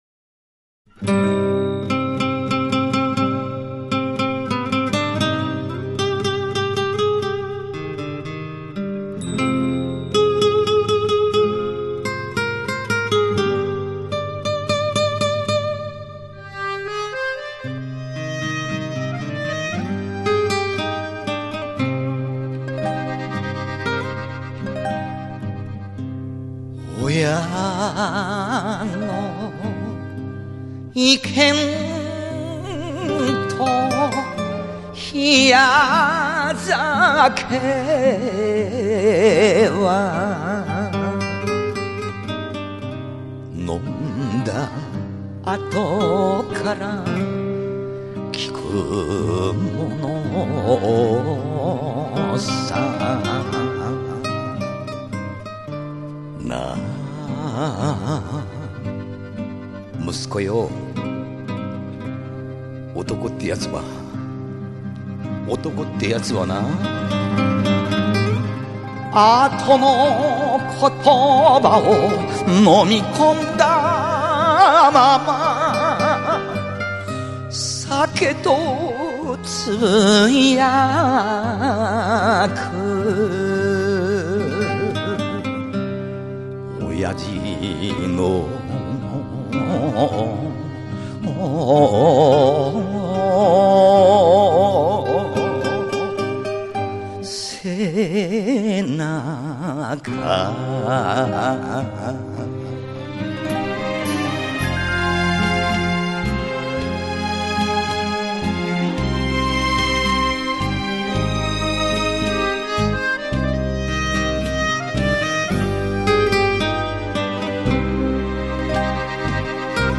代流行曲的唱法熔为一炉，形成了自己独特的演唱技法。